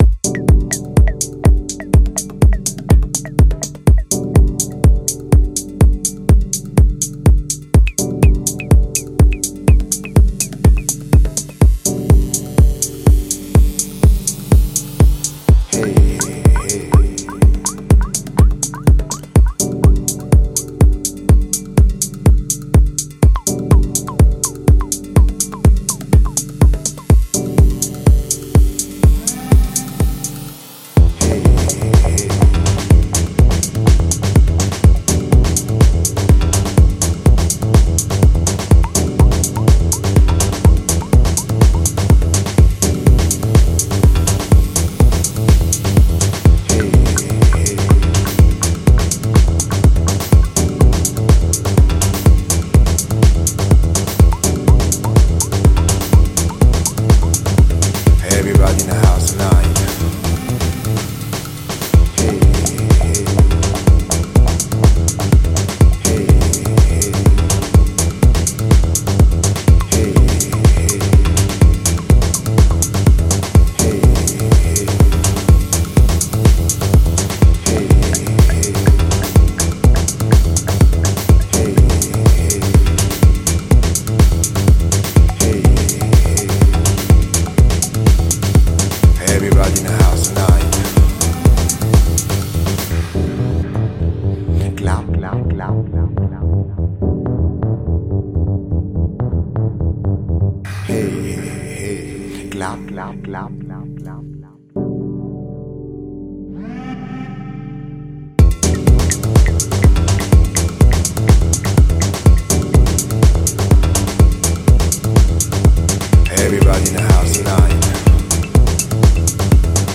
Two tracker with classic house feel all over it.